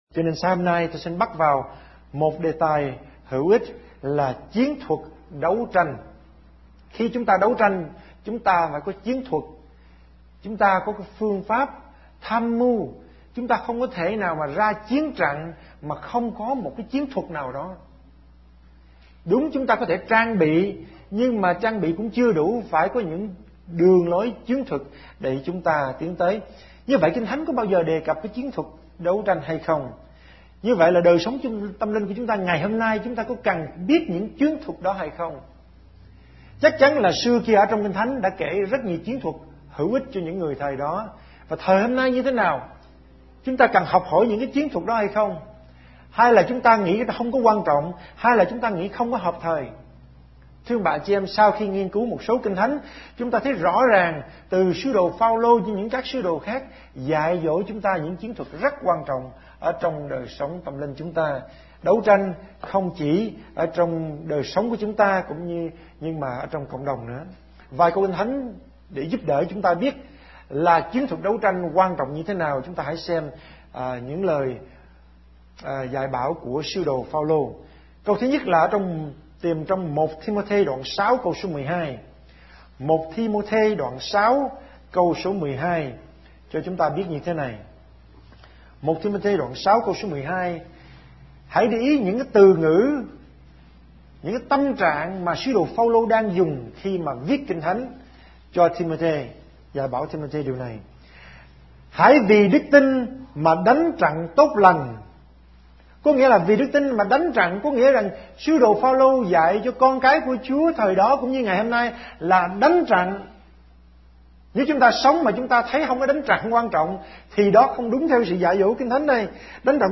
Sermon / Bài Giảng